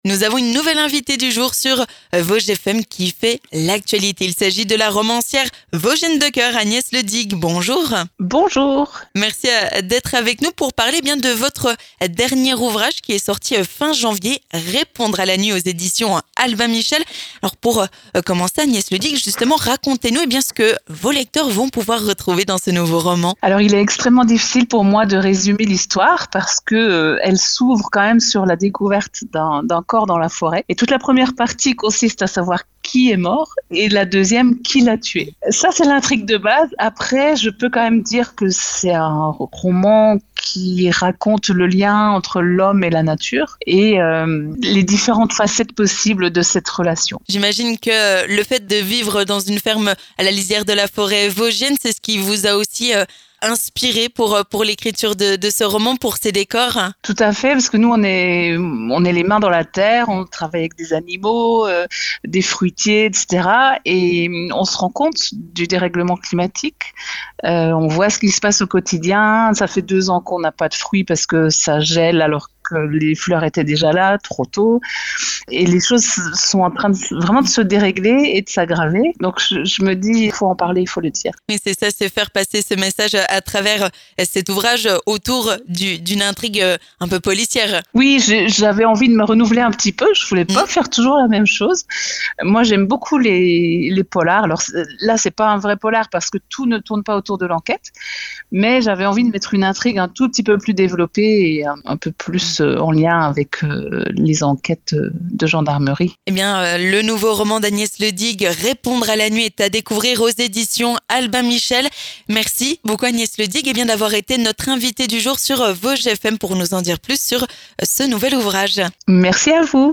L'invité du jour
Et à l'occasion de sa sortie, Agnès Ledig est notre invitée du jour sur Vosges FM.